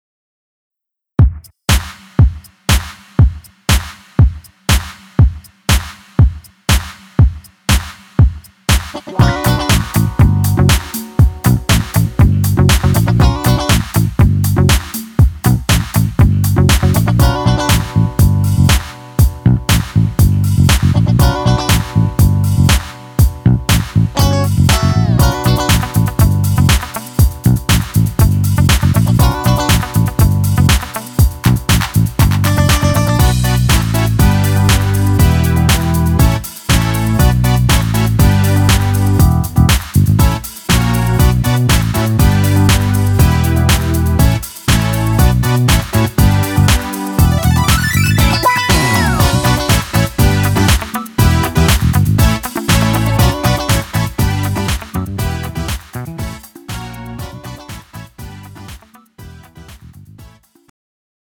음정 원키 2:43
장르 가요 구분 Pro MR